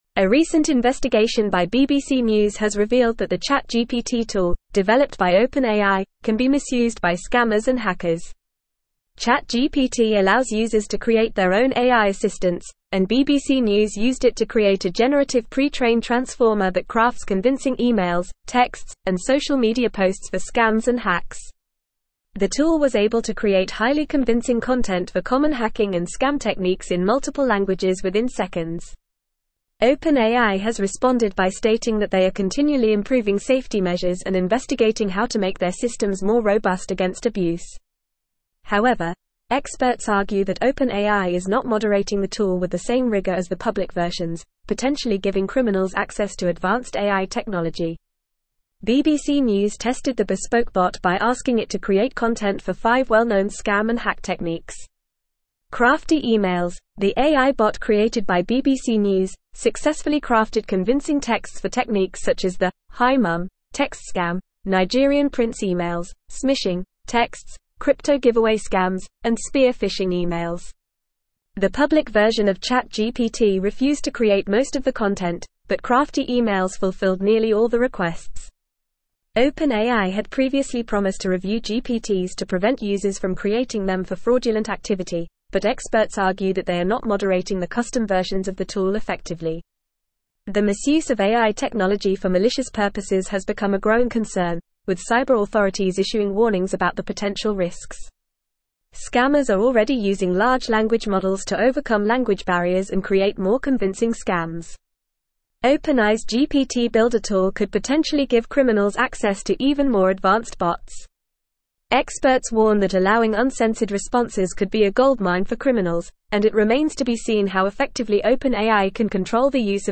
Fast
English-Newsroom-Advanced-FAST-Reading-OpenAIs-ChatGPT-Tool-Raises-Concerns-About-Cybercrime.mp3